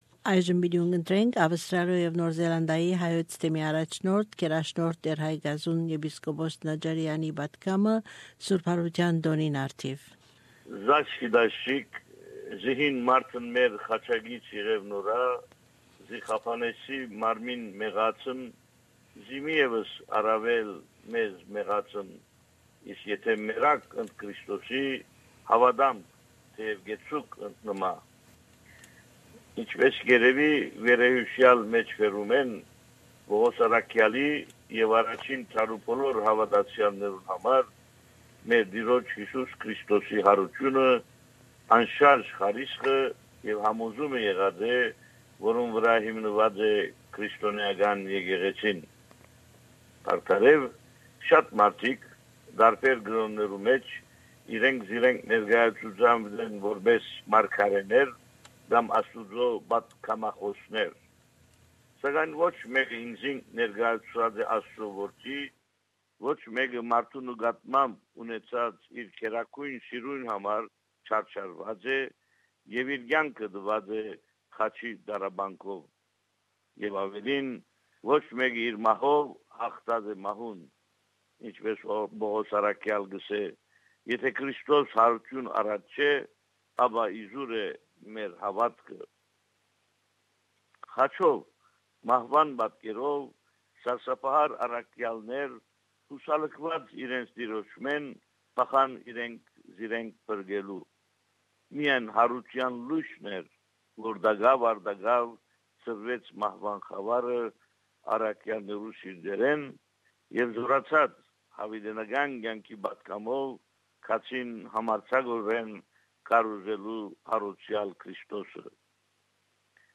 Easter Message by Bishop Haygazoun Najarian Primate of Armenians in Australia and New Zealand.